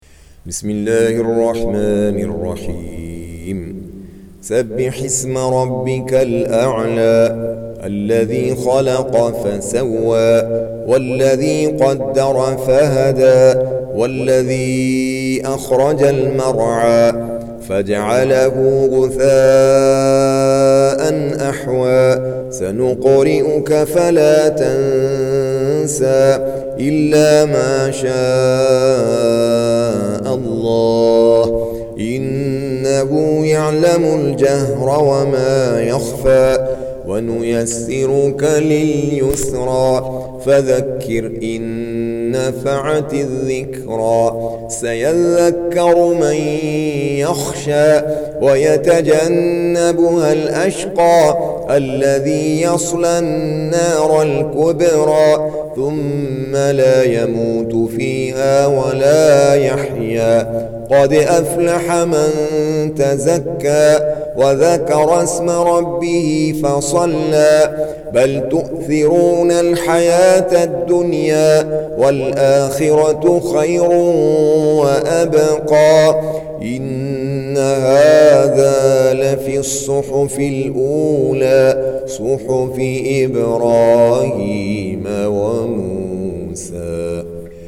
Surah Sequence تتابع السورة Download Surah حمّل السورة Reciting Murattalah Audio for 87. Surah Al-A'l� سورة الأعلى N.B *Surah Includes Al-Basmalah Reciters Sequents تتابع التلاوات Reciters Repeats تكرار التلاوات